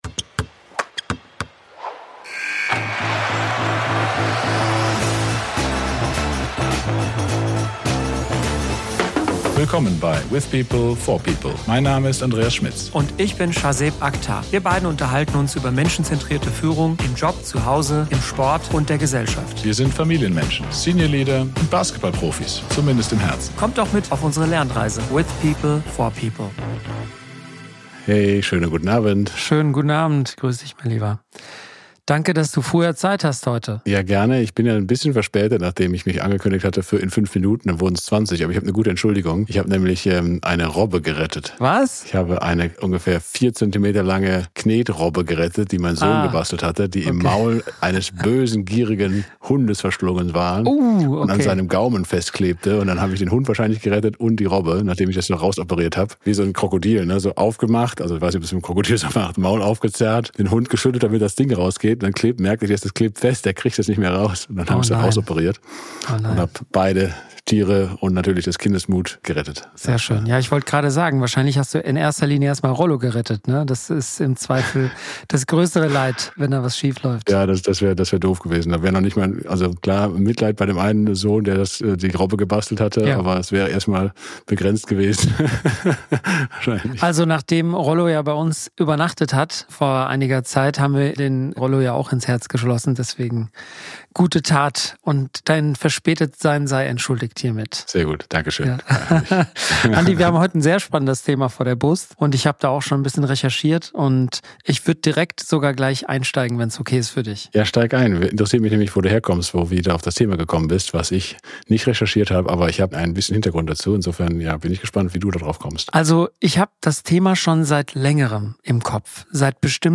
Die beiden diskutieren zudem, welche Verantwortung Unternehmen und Führungskräfte tragen, wenn es um psychologische Sicherheit, realistische Erwartungen und eine gesunde Fehlerkultur geht. Warum Verletzlichkeit keine Schwäche, sondern eine Stärke ist, zieht sich als roter Faden durch das Gespräch.